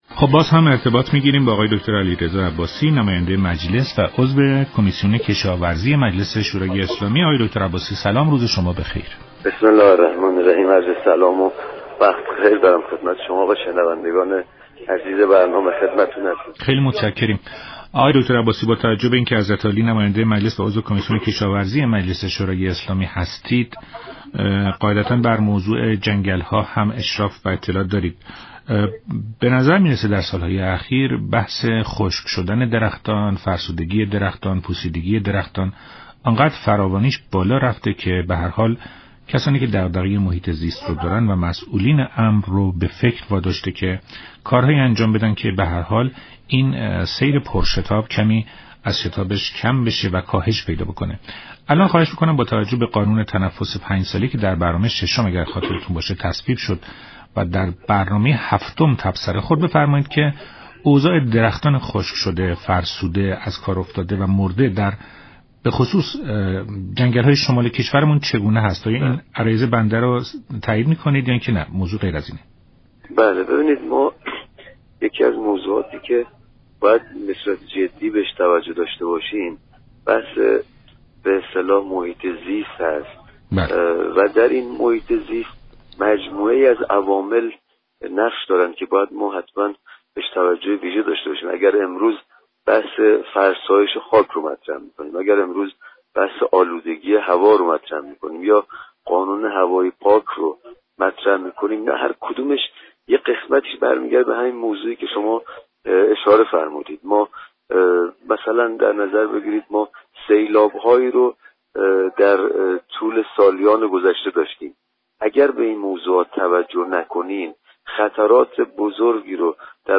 به گزارش شبكه رادیویی ایران، علیرضا عباسی نماینده مجلس و عضو كمیسیون كشاورزی در برنامه ایران امروز درباره خشكی پیش از اندازه درختان گفت: حفظ محیط زیست یك ضرورت مهم است؛ بی‌توجهی به این موضوع كشور را با خطرات جدی مواجه خواهد‌كرد.